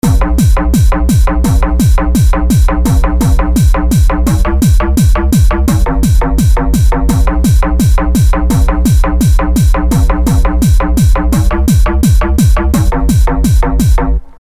网球在地面上弹跳
描述：这是网球在地面上弹跳的记录。 录音机：TASCAM DR40